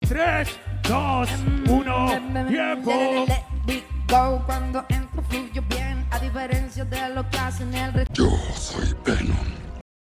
TE SALUDO CON LA VOZ DE VENOM